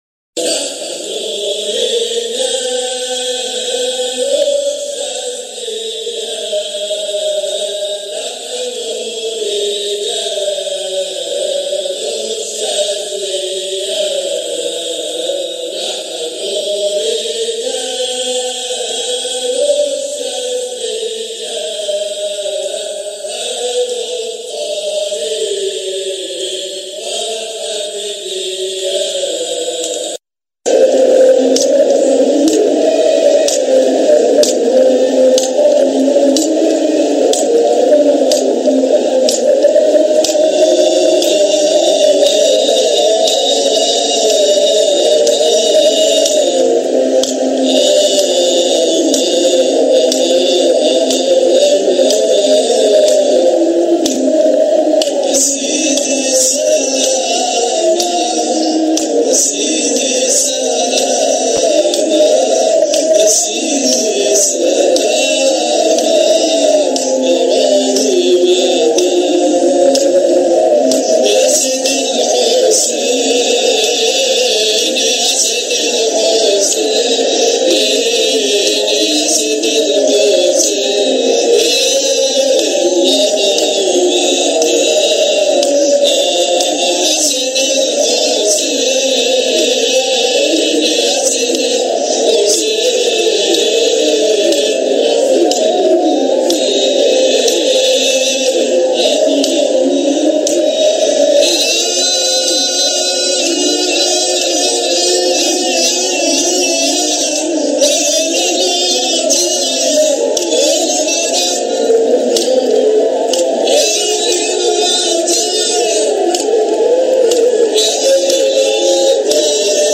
جزء من حلقة ذكر بمولد سيدنا احمد البدوى قدس سره 2018